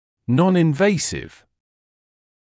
[ˌnɔnɪn’veɪsɪv][ˌнонин’вэйсив]неинвазивный